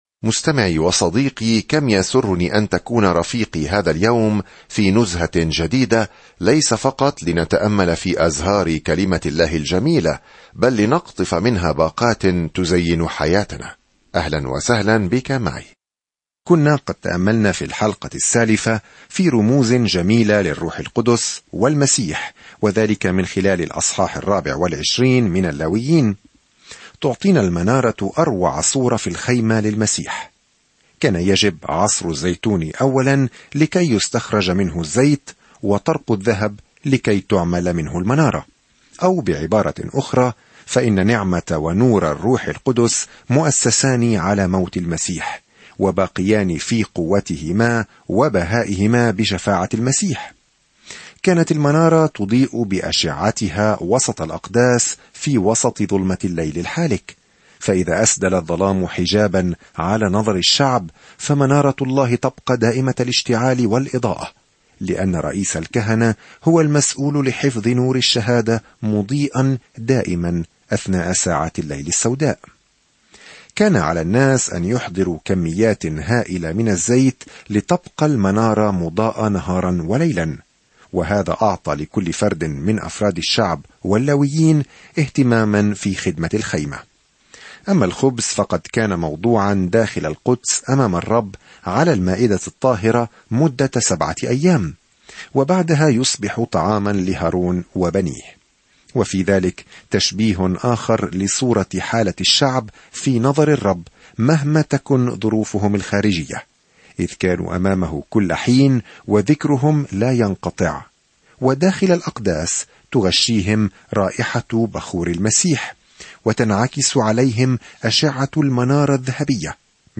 في العبادة والتضحية والتبجيل، يجيب سفر اللاويين على هذا السؤال بالنسبة لإسرائيل القديمة. سافر يوميًا عبر سفر اللاويين وأنت تستمع إلى الدراسة الصوتية وتقرأ آيات مختارة من كلمة الله.